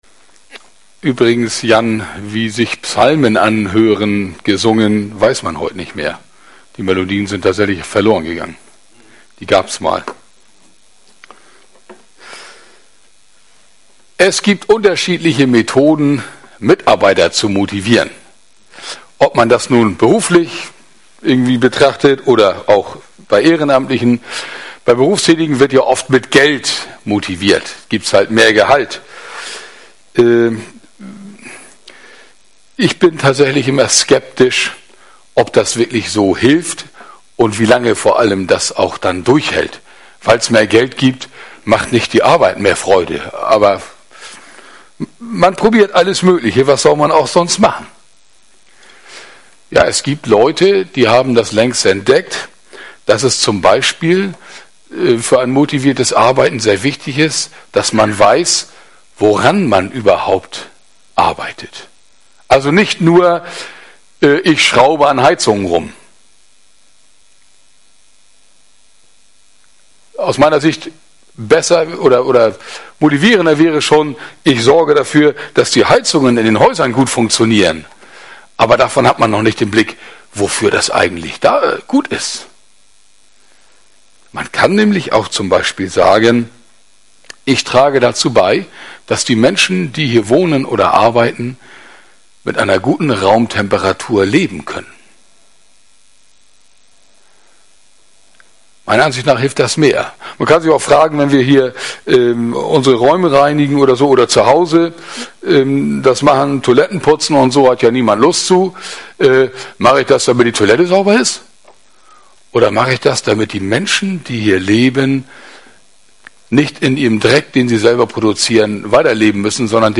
Predigt vom 23. Oktober 2022 - Brunchgottesdienst